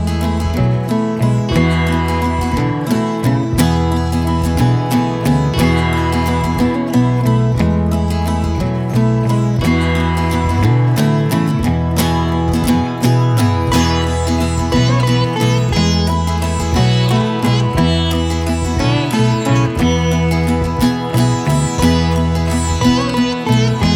no BV Pop (1960s) 2:02 Buy £1.50